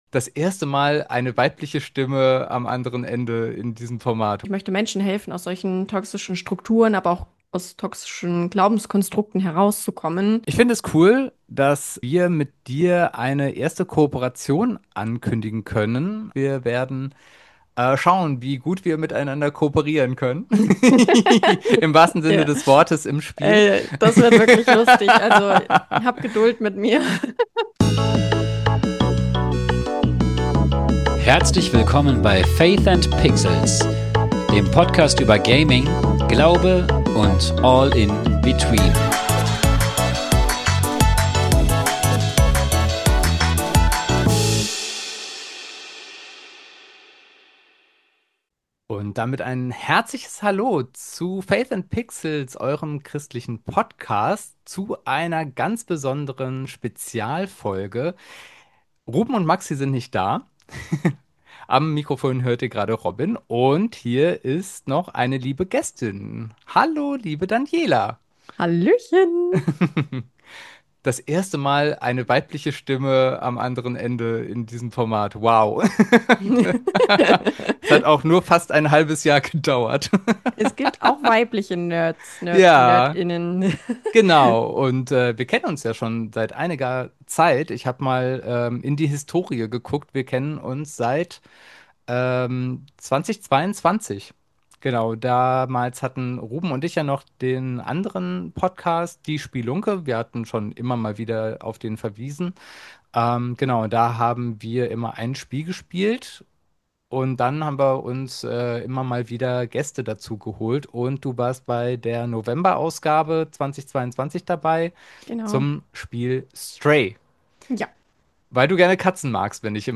Zum ersten Mal seit Bestehen von Faith and Pixels hören wir eine weibliche Stimme im Podcast.
Ein entspannter Talk mit einer tollen Frau mit wichtiger Botschaft!